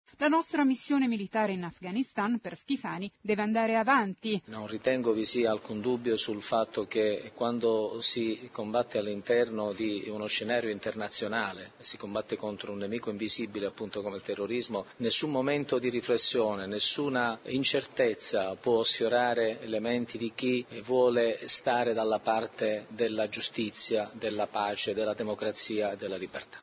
Ero in macchina e ascoltavo il Gr2 delle 19:30.
Intevista Schifani